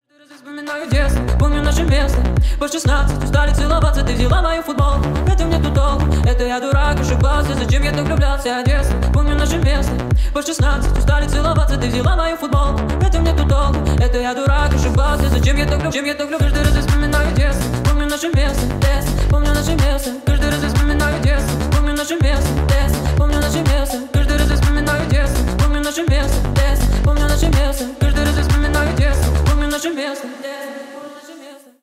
Ремикс # Поп Музыка
ритмичные